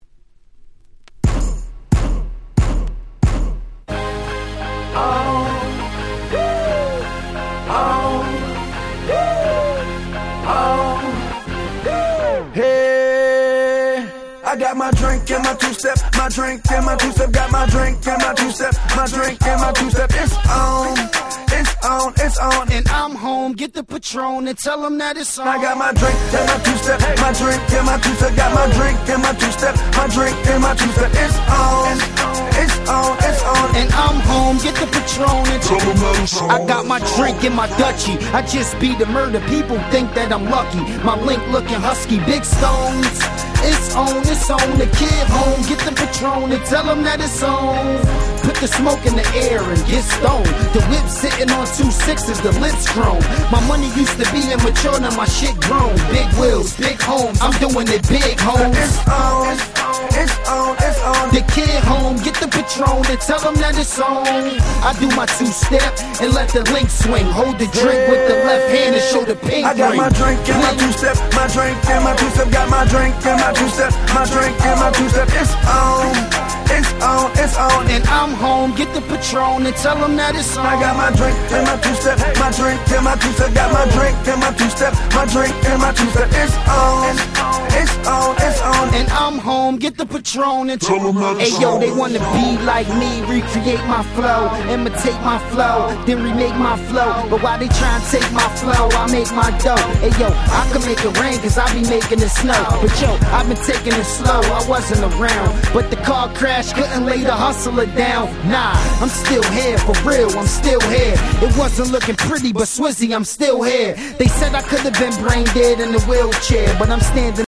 07' Smash Hit Hip Hop !!